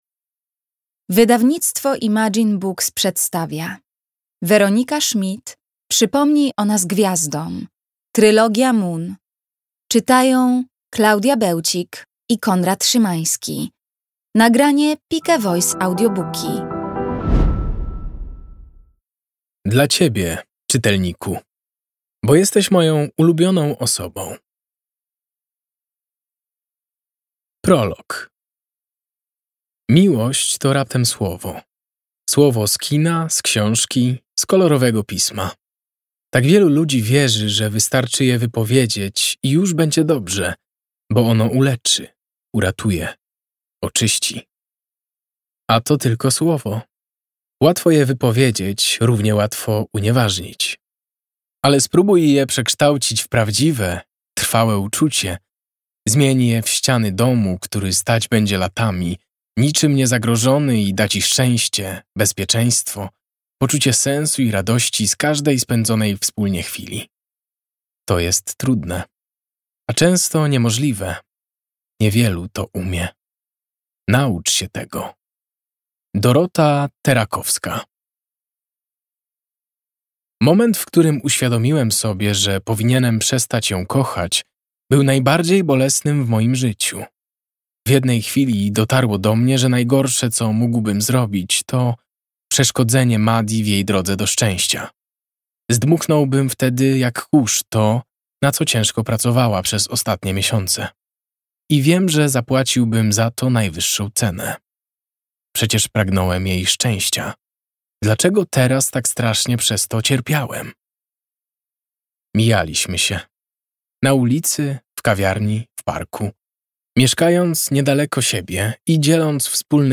Audiobook + książka Przypomnij o nas gwiazdom, Weronika Schmidt.